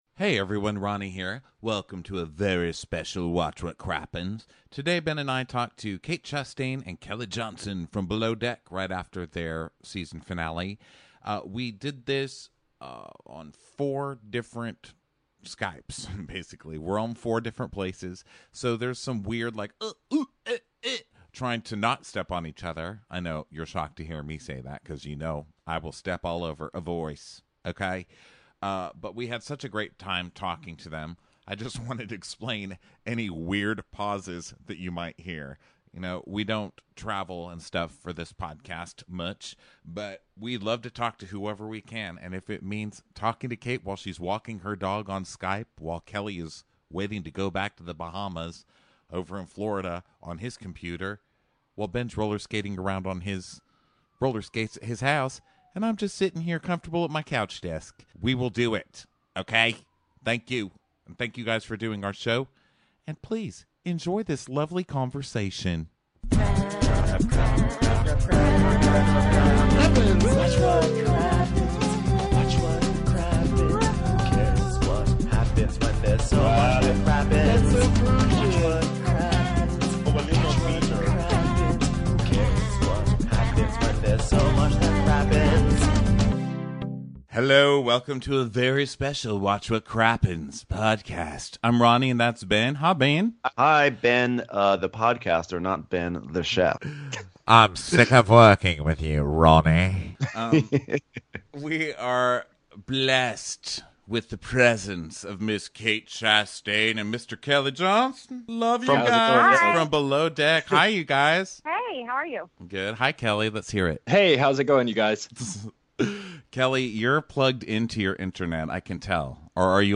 #354: Below Deck Special: Kate Chastain and Kelley Johnson Interview